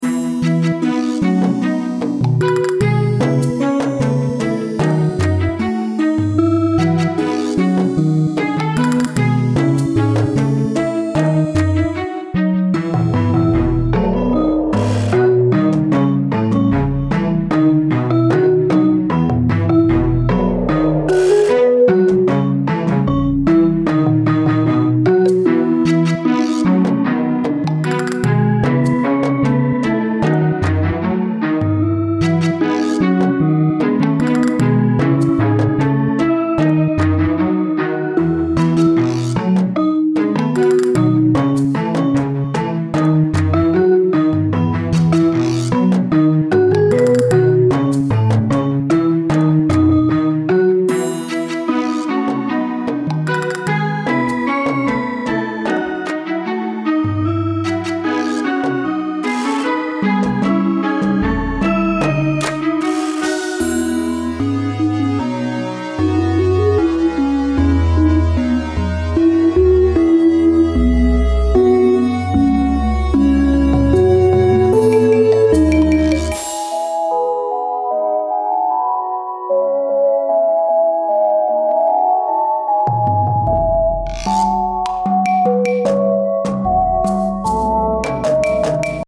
I envision this tune in an innocent town, tinkerers area, or other playful kind of zone.